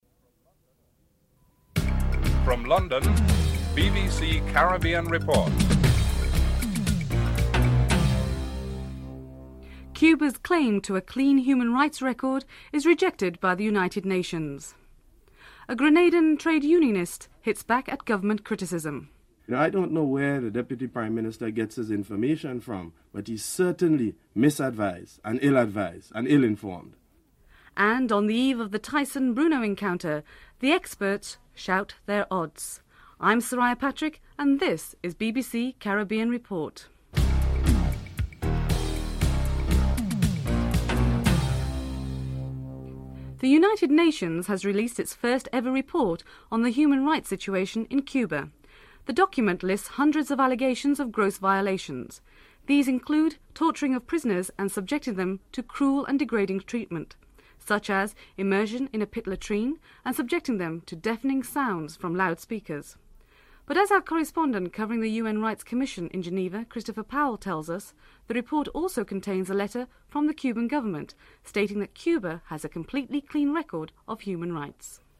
The British Broadcasting Corporation
4. Financial News (06:37-07:27)